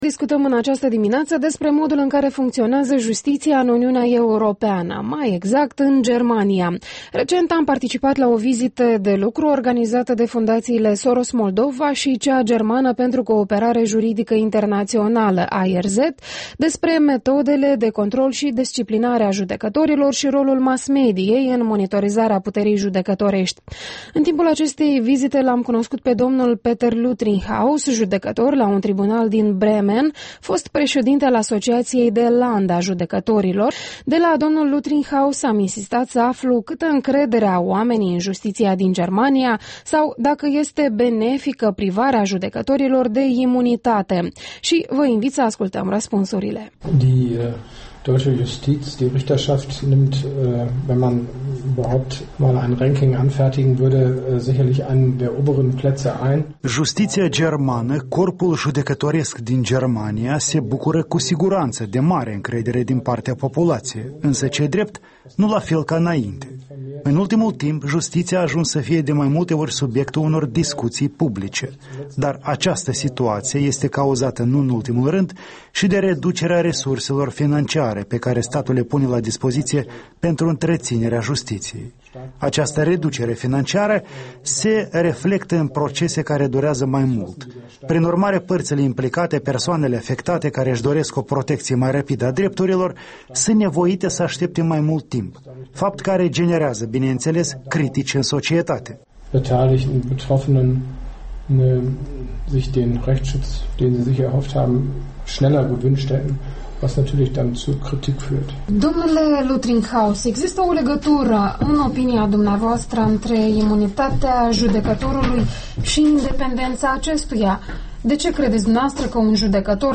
Interviul dimineții: cu Peter Lüttringhaus, judecător la un Tribunal din Bremen